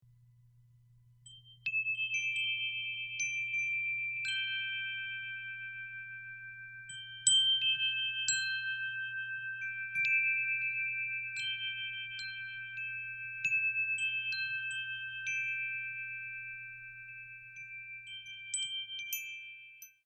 Tuned according to the Golden Ratio found throughout nature, this chime has a personality all its own.
Cherry finish Ash wood, 4 square black tubes, rust finish steel windcatcher, genuine stone accent.